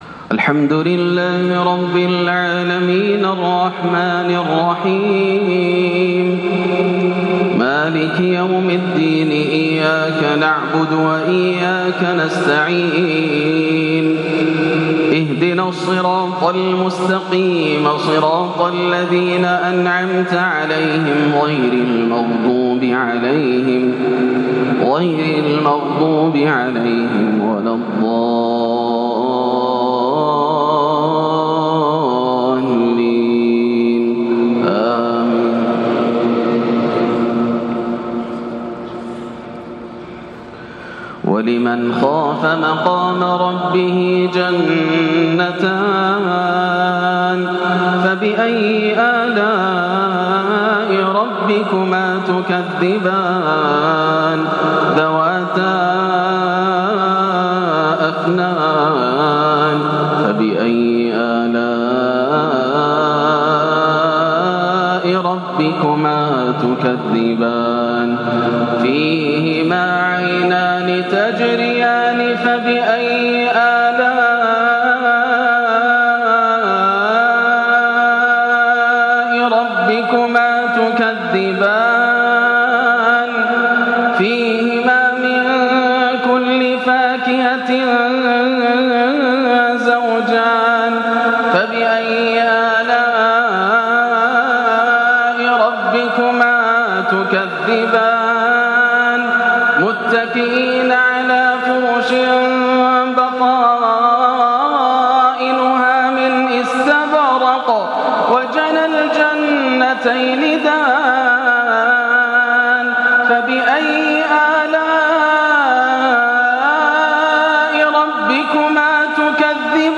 وصف نعيم الجنان من سورة الرحمن - حجاز كار مؤثر من صلاة الجمعة 11-8 > عام 1439 > الفروض - تلاوات ياسر الدوسري